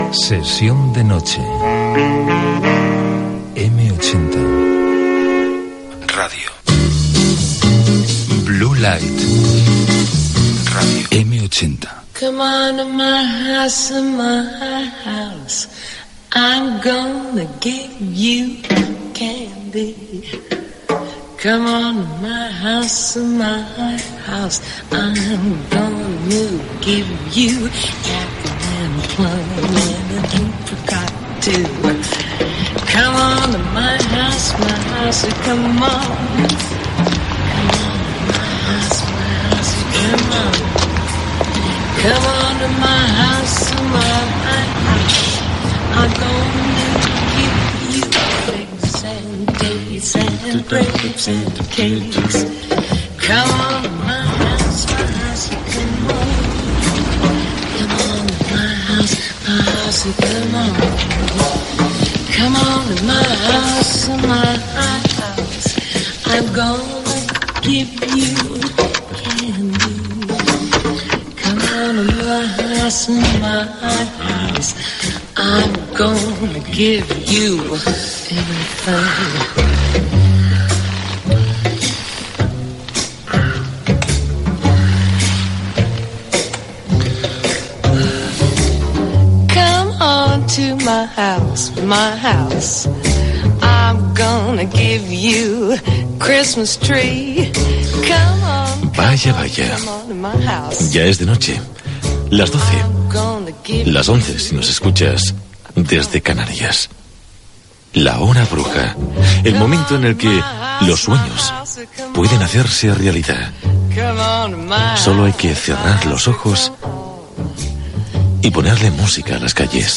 Careta del programa, presentació i benvinguda Gènere radiofònic Musical